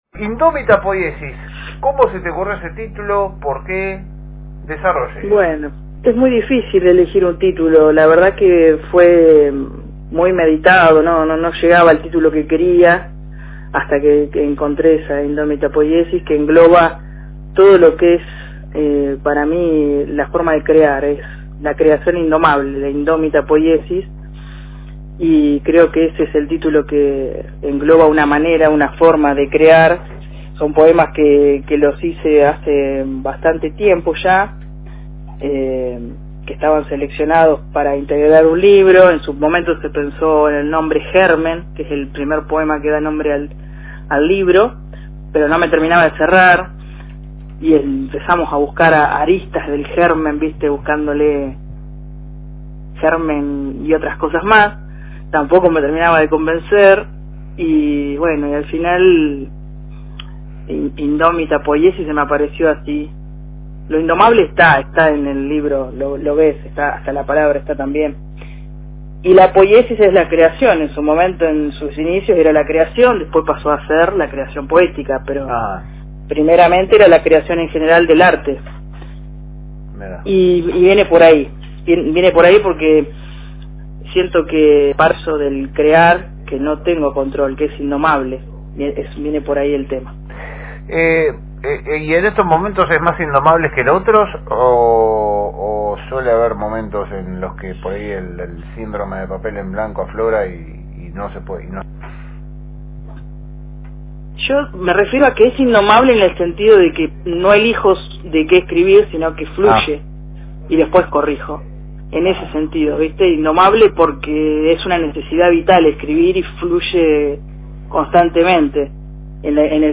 La Segunda Mañana conversamos largo y tendido con la poeta y escritora